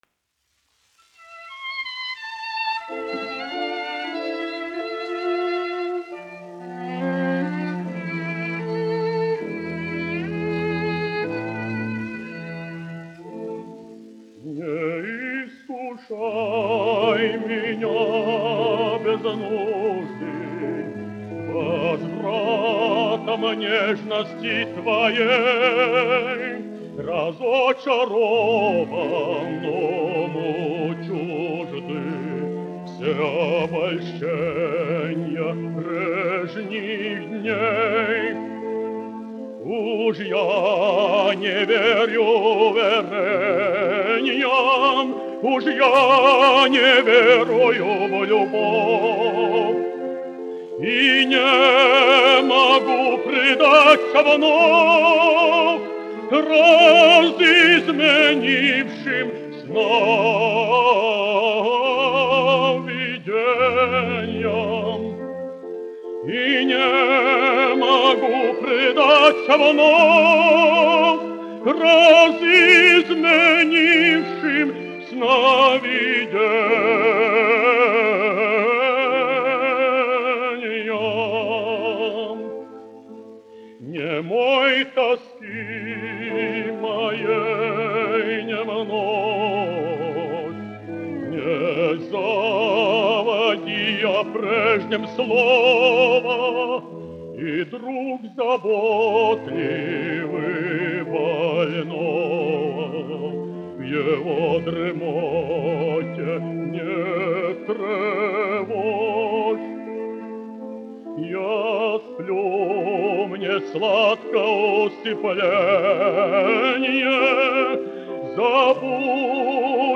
Kaktiņš, Ādolfs, 1885-1965, dziedātājs
1 skpl. : analogs, 78 apgr/min, mono ; 25 cm
Dziesmas (zema balss) ar instrumentālu ansambli
Romances (mūzika)
Skaņuplate